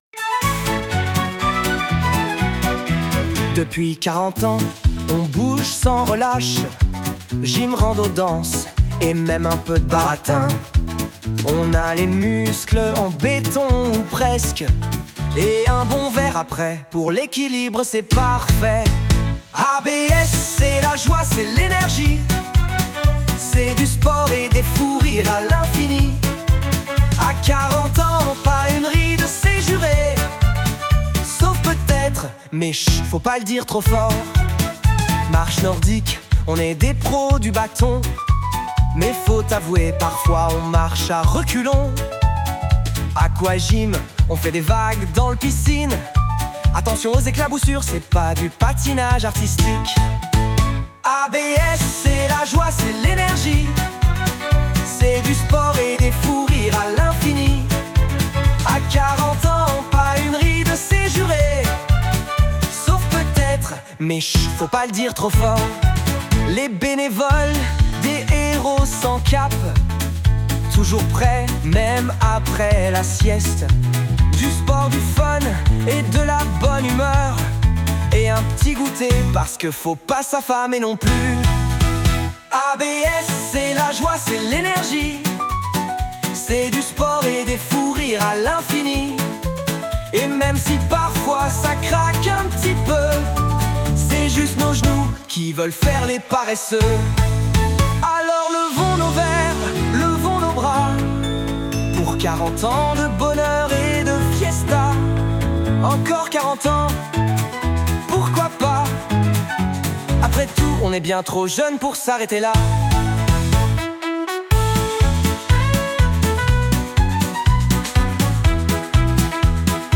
40_ans_et_toujours_en_forme_pop_v1.mp3